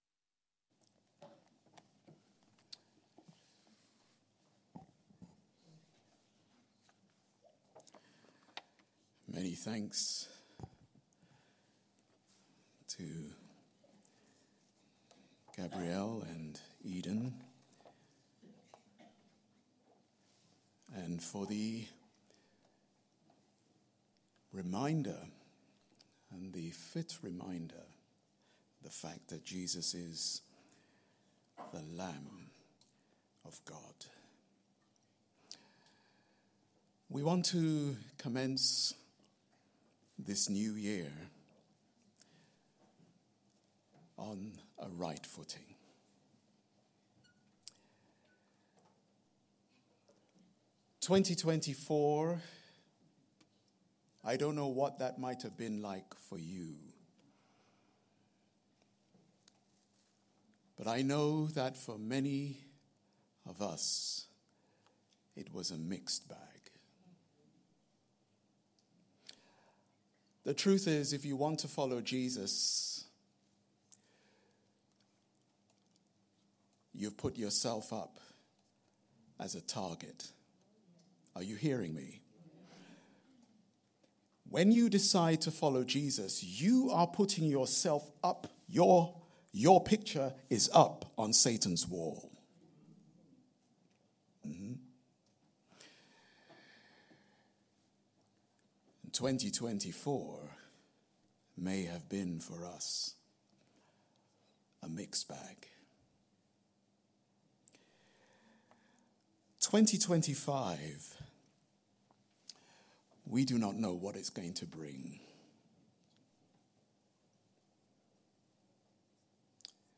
Family Service Recordings